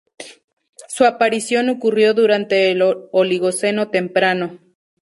tem‧pra‧no
/temˈpɾano/